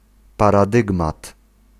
Ääntäminen
UK : IPA : /ˈpæɹ.ə.daɪm/ Tuntematon aksentti: IPA : /ˈpɛɹ.ə.daɪm/